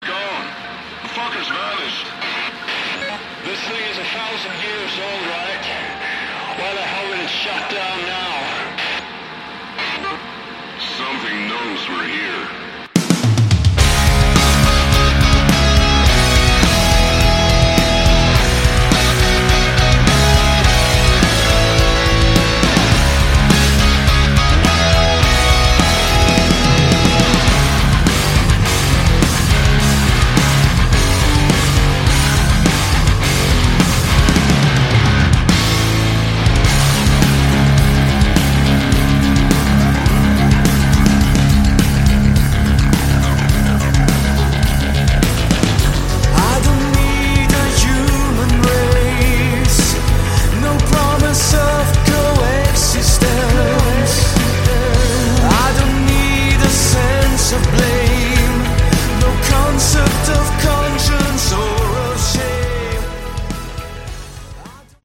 Category: Symphonic Hard Rock
lead vocals, guitars, bass
synthesizers, vocals